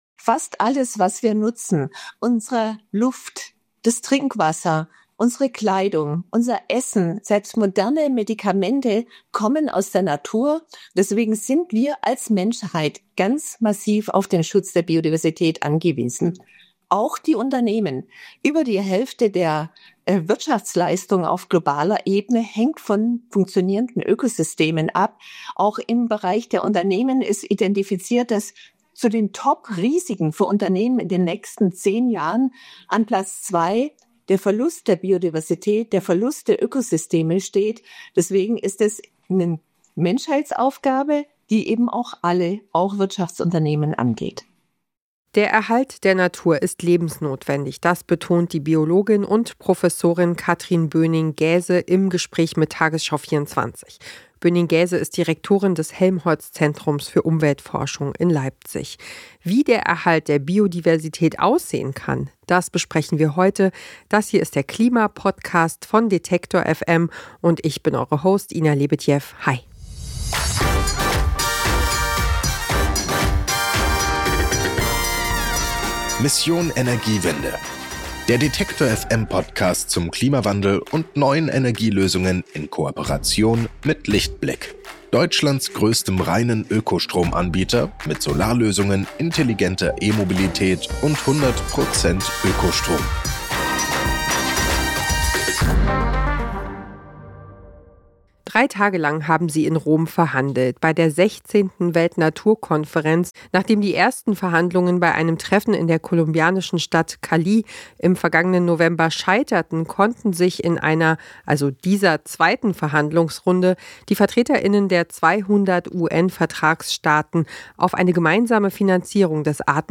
Ein Besuch auf einer Streuobstwiese in Thüringen.